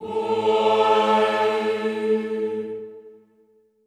Index of /90_sSampleCDs/Best Service - Extended Classical Choir/Partition D/HOO-AHH-EHH
HOO-AH  A2-R.wav